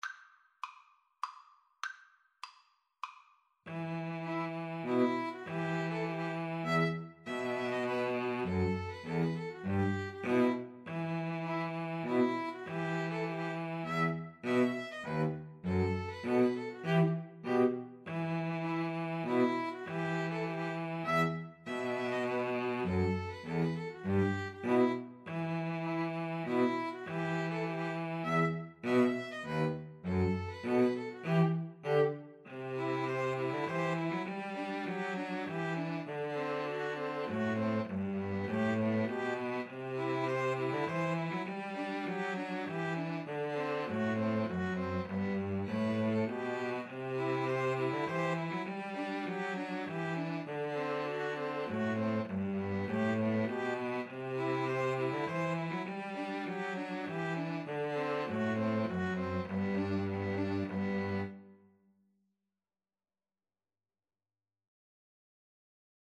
Violin 1Violin 2Cello
E minor (Sounding Pitch) (View more E minor Music for 2-Violins-Cello )
3/4 (View more 3/4 Music)
Traditional (View more Traditional 2-Violins-Cello Music)
Swedish